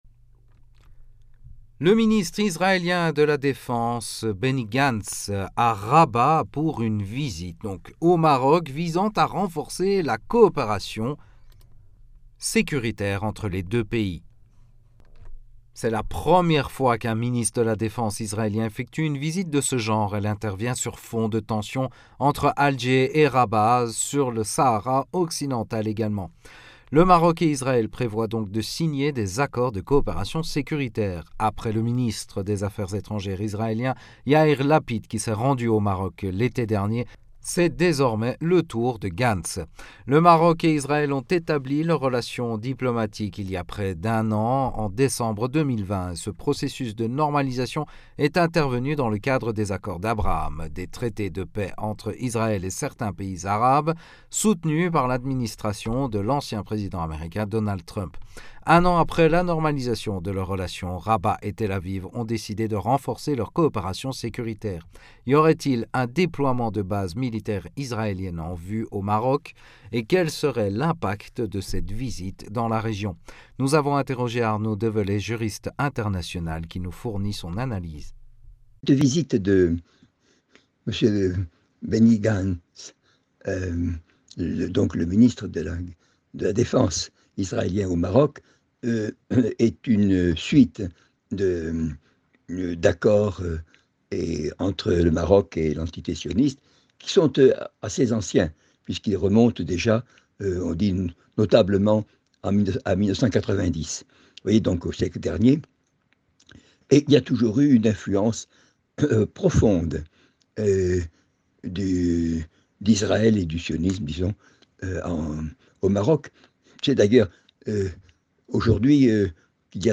Mots clés Afrique Israël interview Eléments connexes Pourquoi Israël craint-il l’exercice naval conjoint de l’Égypte et de la Turquie ?